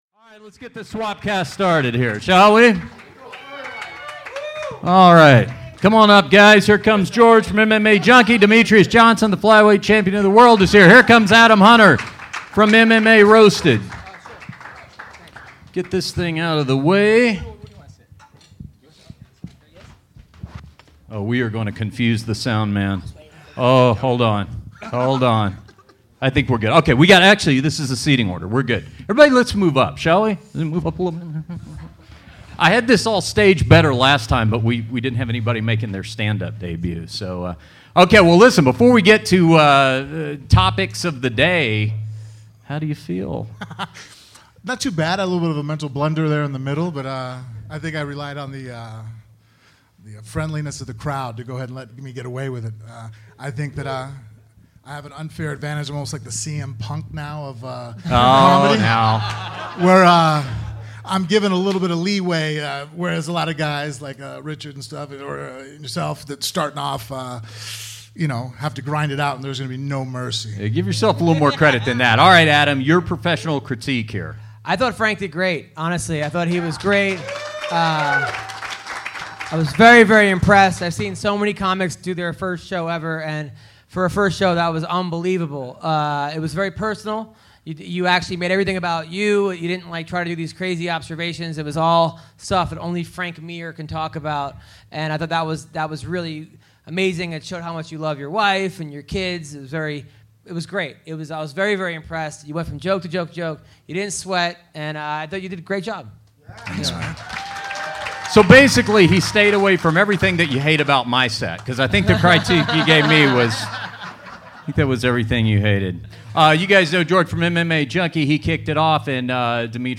We talk about UFC 202, Jon Jones, Brock Lesnar, and more. Frank Mir does a live Stand UP Comedy Set and more.